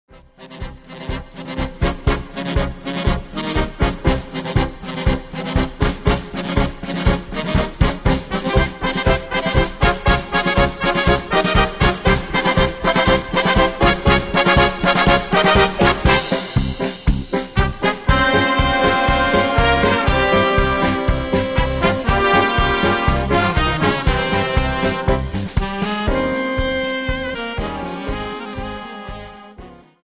Dance: Paso Doble 60 Song